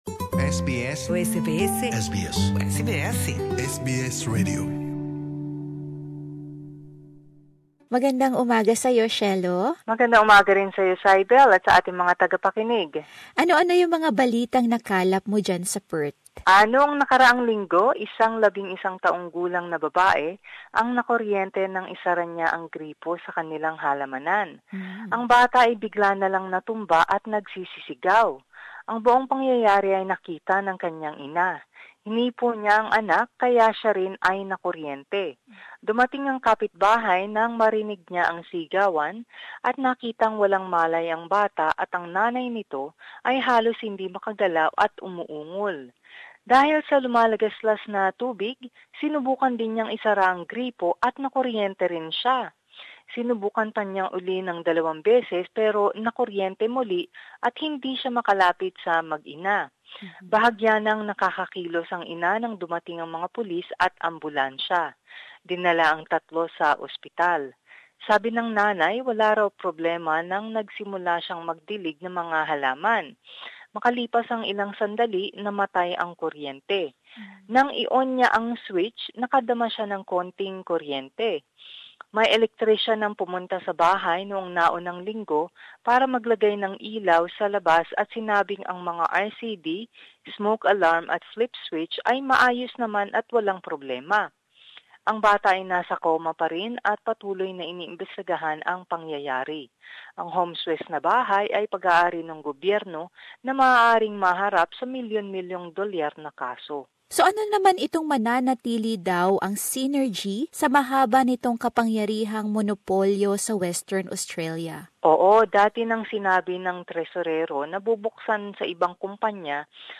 SBS News in Filipino